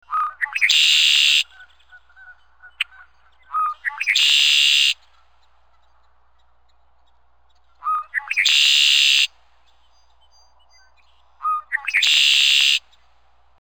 After reading about the latter two subjects online, I decided to constrain my problem space to detecting red-winged black birds in audio files, due to the fact that they have a very distinct song.
Red-winged Blackbird song
red-winged-blackbird-call.mp3